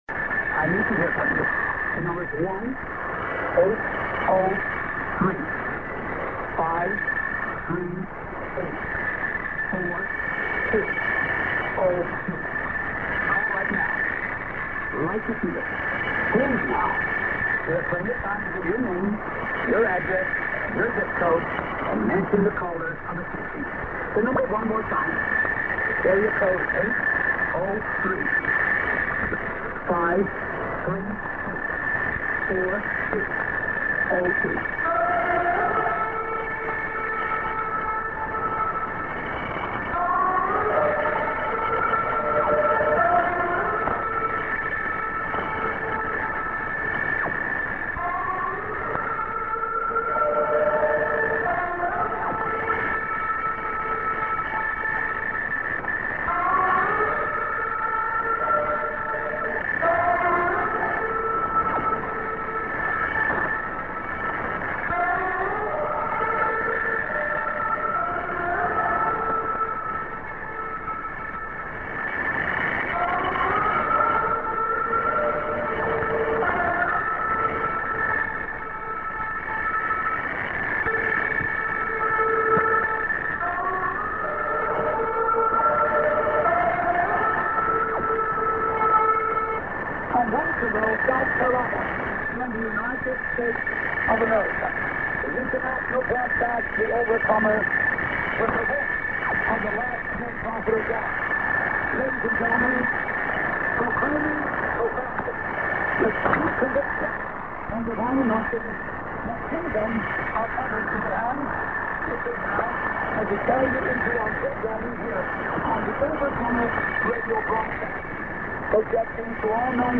End TS->ID(men)->s/off